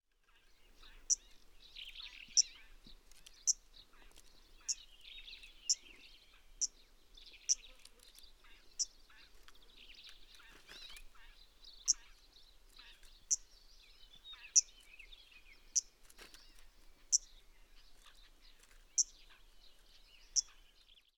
PFR07756, Arctic Warbler Phylloscopus borealis, call,
Erdenesant, Mongolia, Telinga parabolic reflector